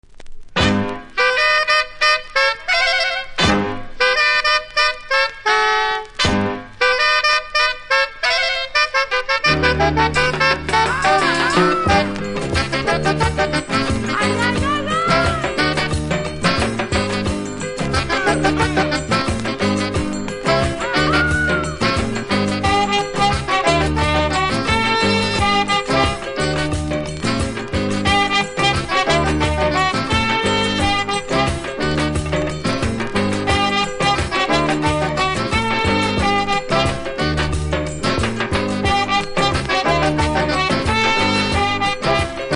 キズそこそこありますがノイズは少なく気にならない程度。